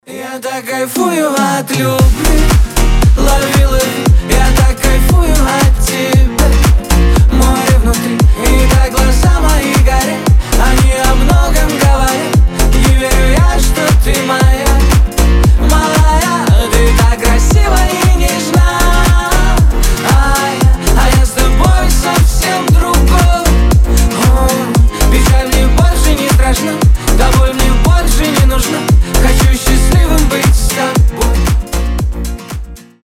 Гитара
Поп